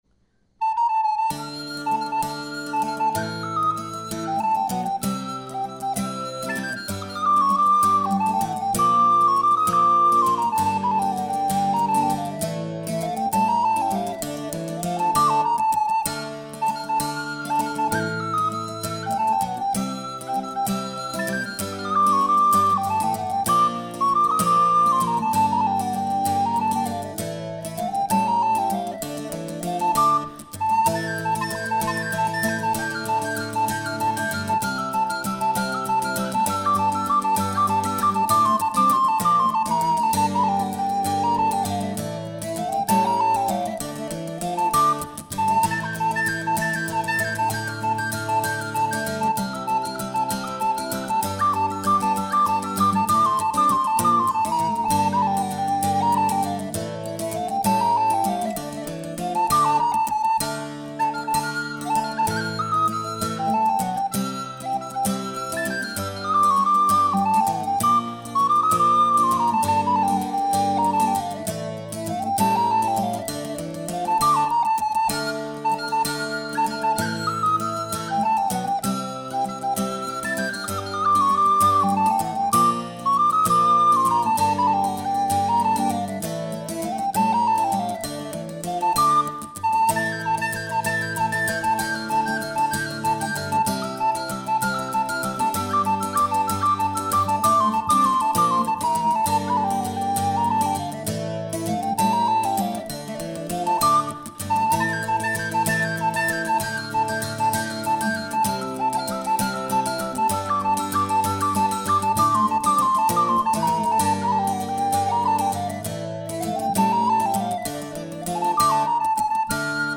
Erledanz joue pour le Bal Folk.
Erledanz_Walzer.mp3